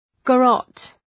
Shkrimi fonetik {gə’rɒt}
garrotte.mp3